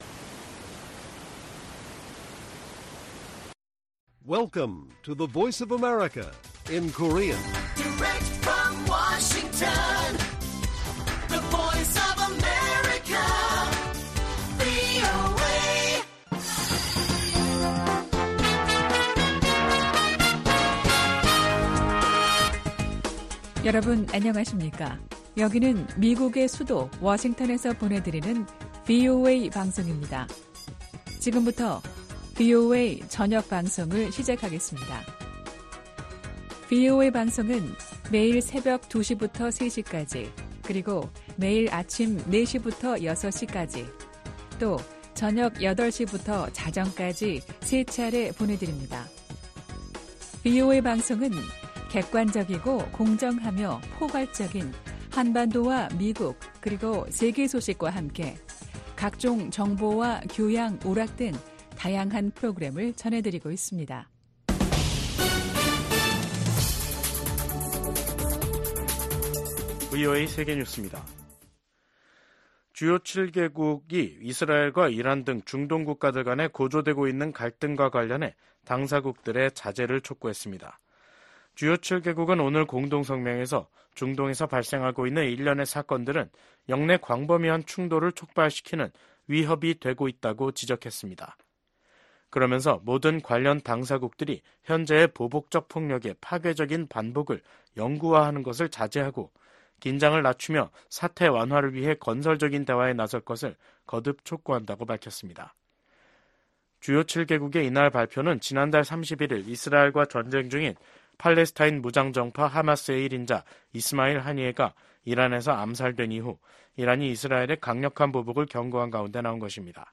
VOA 한국어 간판 뉴스 프로그램 '뉴스 투데이', 2024년 8월 5일 1부 방송입니다. 북한이 핵탄두 장착이 가능한 신형 전술탄도미사일 발사대를 대규모 전방 배치한다고 발표했습니다.